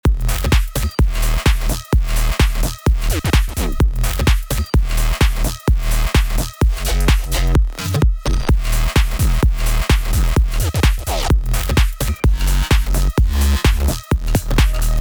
Absolute desruction!
bass house construction kit drops